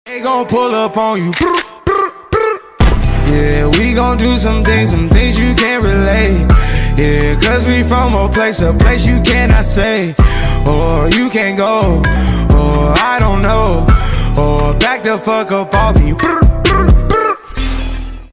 Miaw sound effects free download